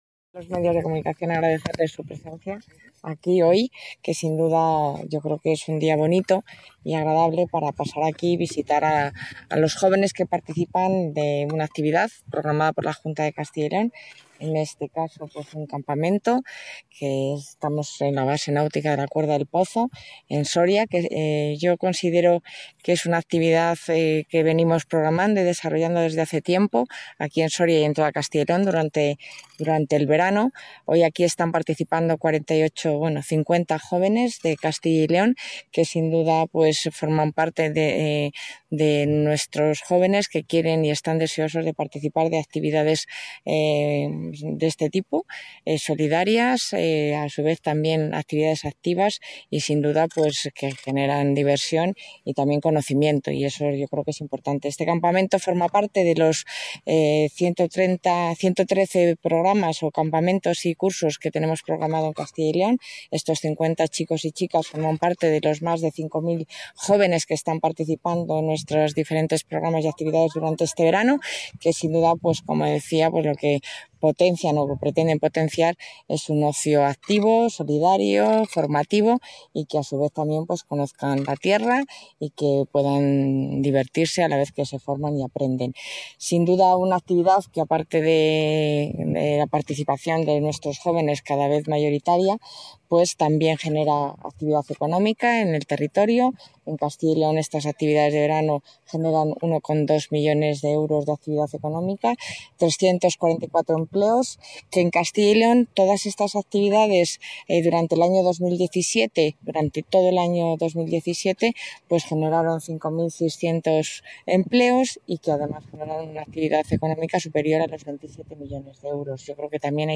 Declaraciones de la consejera de Familia e Igualdad de Oportunidades.